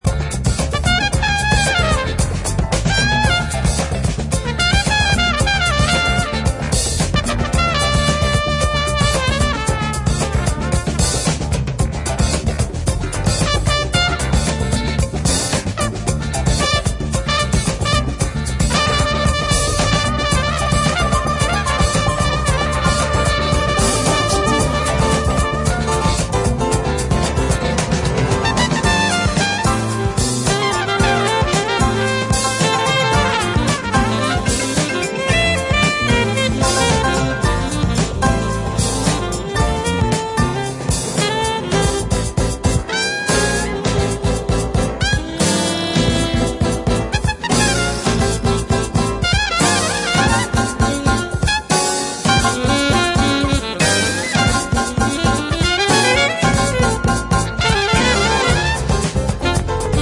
Jazz-funk